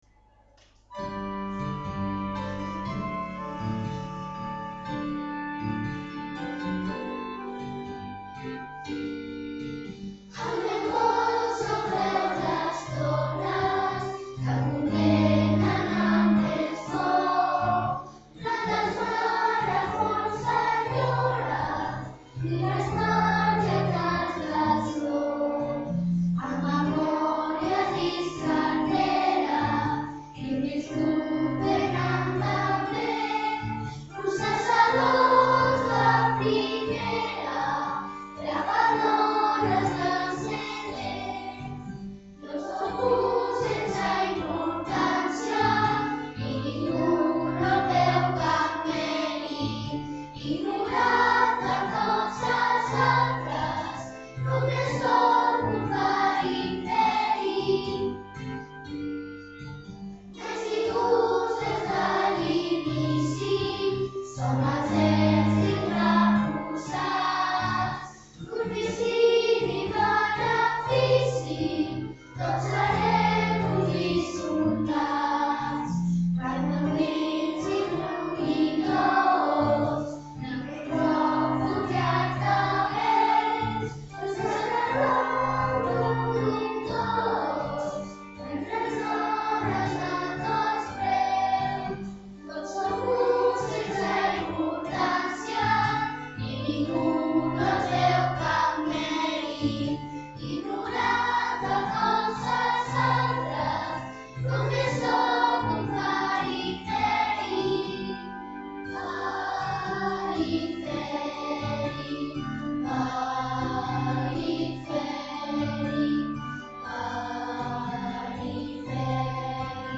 A continuació us posem totes les cançons de la cantata el Ratolí Electrònic text d’en Jesús Nieto i música d’en Ricard Gimeno que els alumnes de 4t hem enregistrat a l’escola .